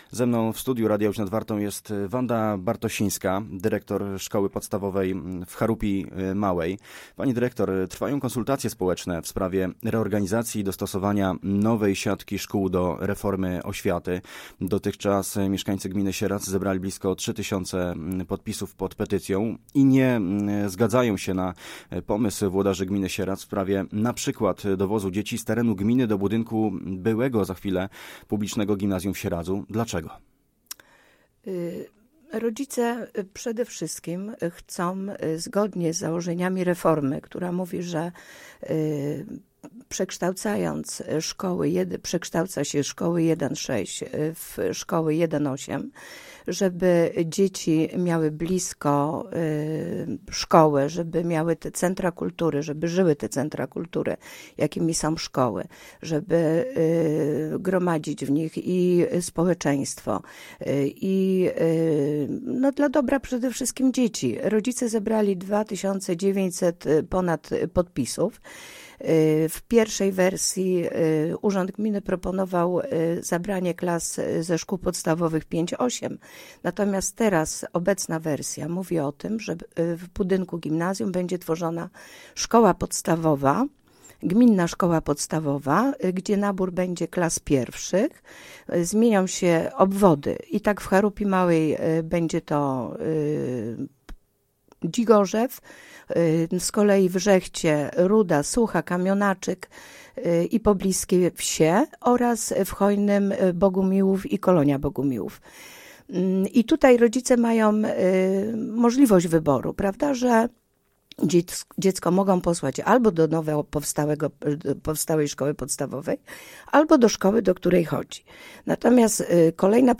Posłuchaj całej rozmowy: Nazwa Plik Autor – brak tytułu – audio (m4a) audio (oga) Warto przeczytać W gminie Moszczenica powstanie odwiert geotermalny 3 lipca 2025 Dramatyczne chwile w piotrkowskim szpitalu.